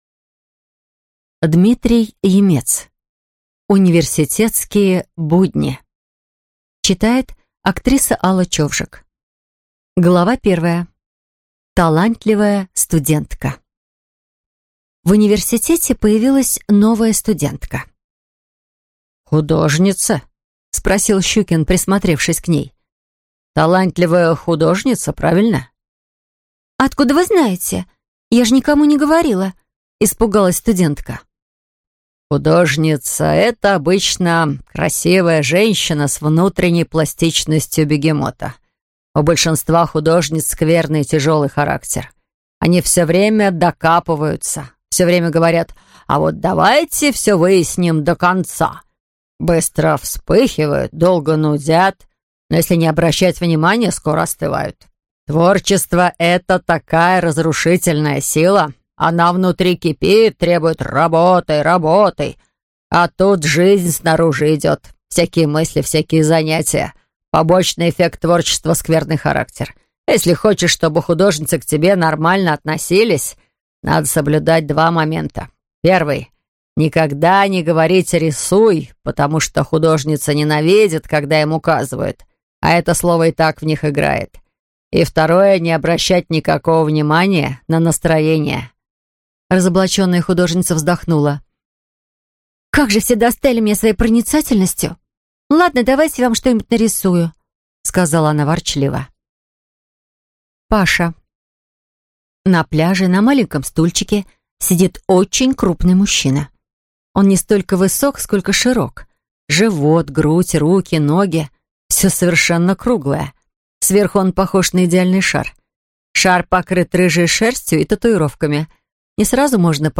Аудиокнига Университетские будни | Библиотека аудиокниг